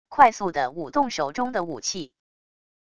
快速的舞动手中的武器wav音频